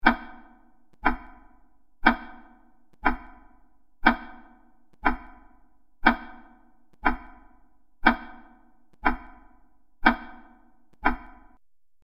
关于科技时钟音效的PPT演示合集_风云办公